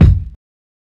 Kick (NaS).wav